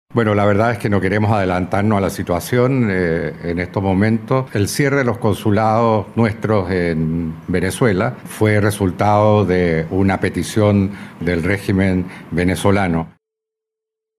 El canciller Alberto van Klaveren afirmó que todavía no es posible hablar de ese escenario y recordó que la ruptura fue solicitada por el propio régimen venezolano.